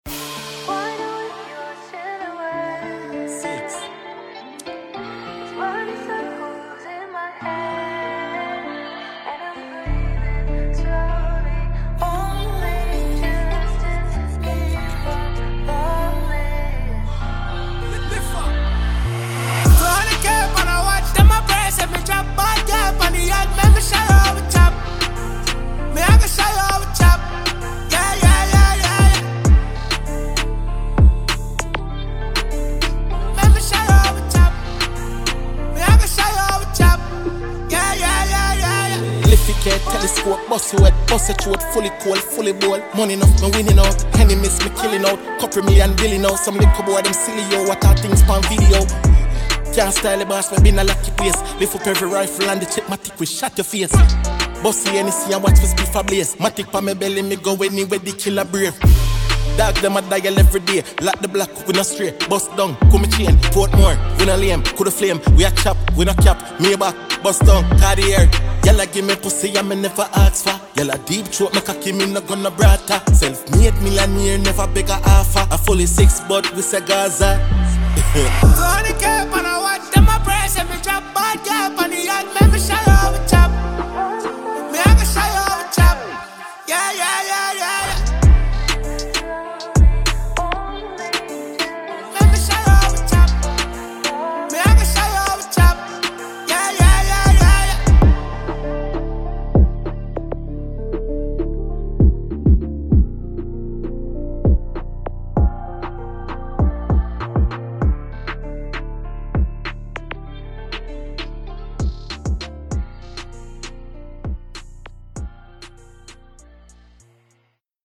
Award winning Jamaican dancehall musician